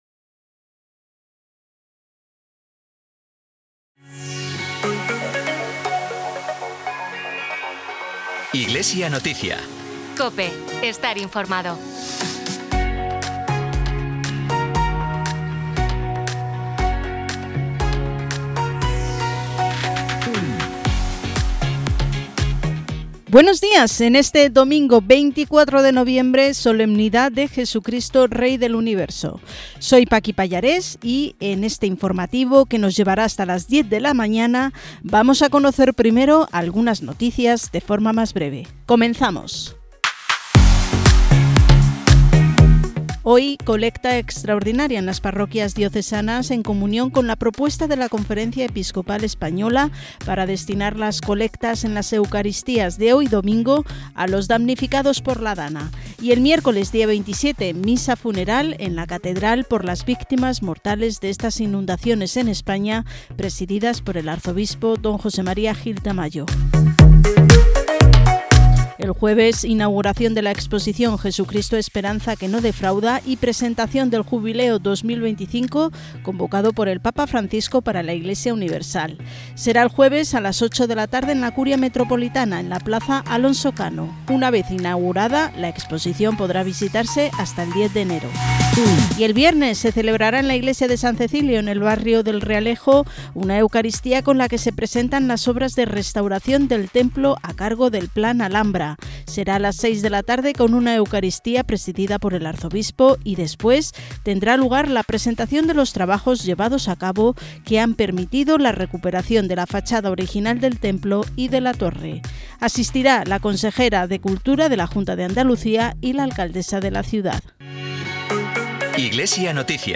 Programa emitido en COPE Granada y COPE Motril el 24 de noviembre de 2024.